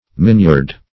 miniard - definition of miniard - synonyms, pronunciation, spelling from Free Dictionary Search Result for " miniard" : The Collaborative International Dictionary of English v.0.48: Miniard \Min"iard\, a. Migniard.